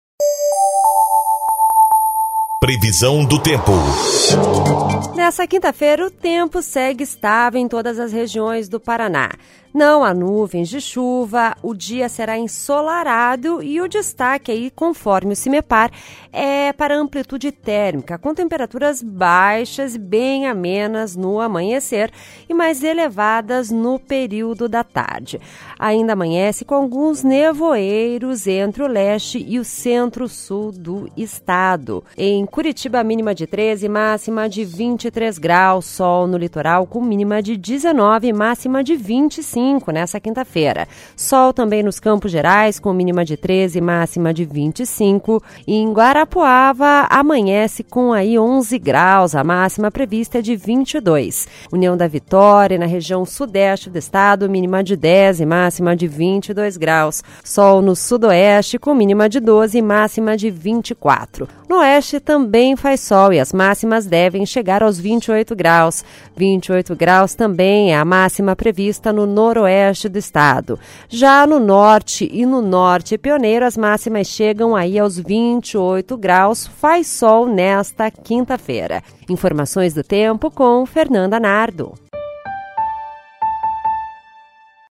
Previsão do Tempo (25/05)